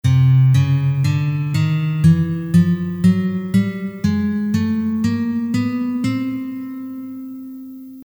Right now, if we start on C and play every note we can until we arrive at another C an octave higher, we’ll play 12 notes, known as the chromatic scale:
C  C#  D  D#  E  F  F#  G  G#  A  A#  B  C
It won’t sound very musical.
chromatic-scale.mp3